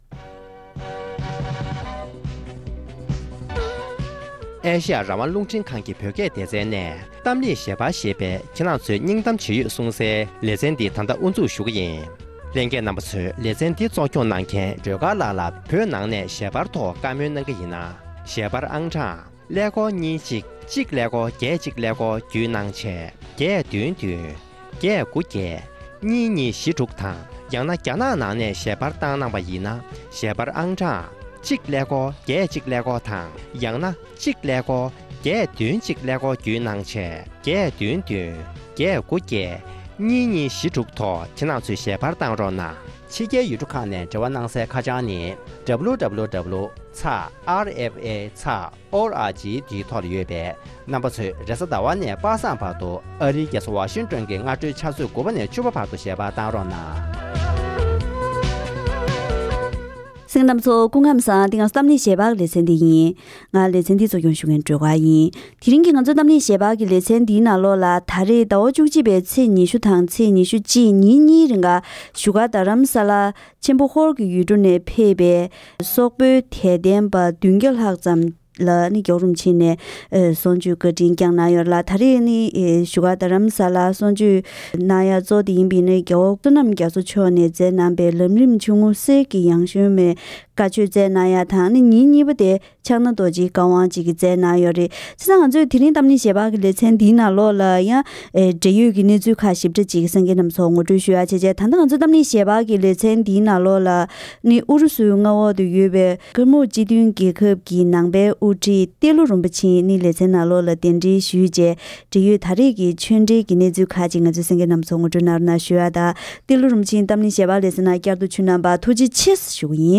༄༅། །དེ་རིང་གི་གཏམ་གླེང་ཞལ་པར་ལེ་ཚན་ནང་།